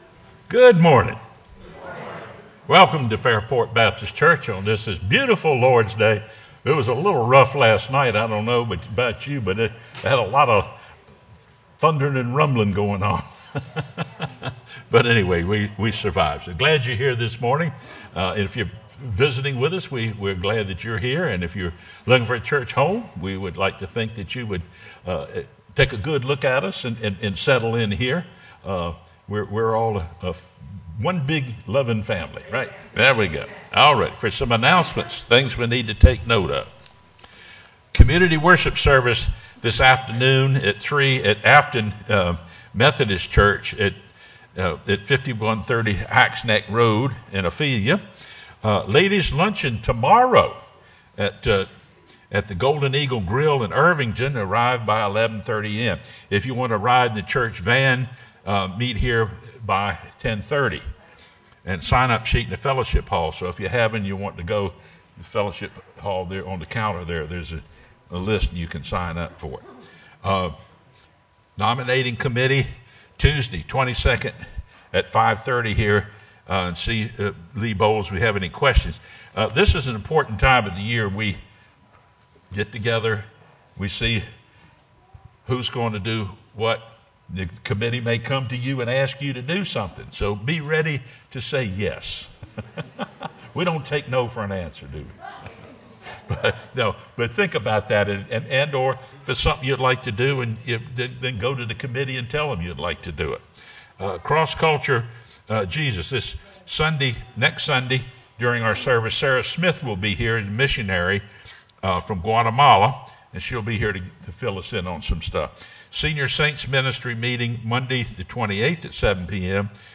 sermonJuly20-CD.mp3